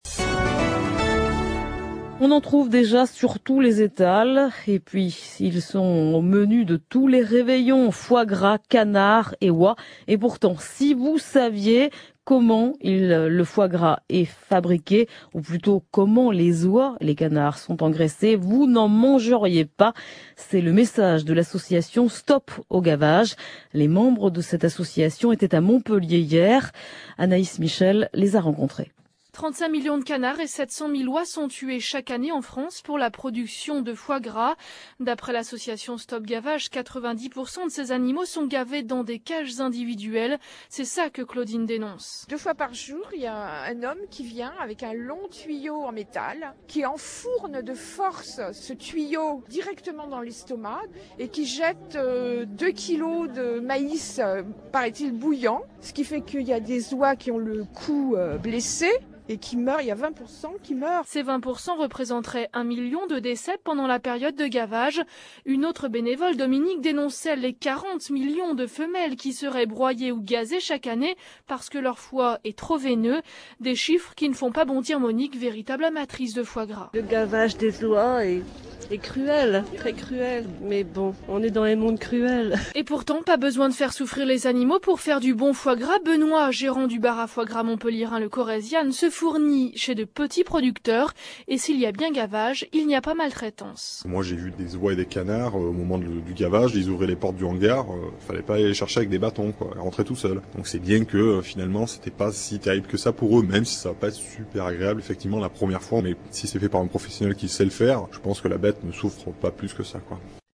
Reportage radio suite à notre passage à Montpellier.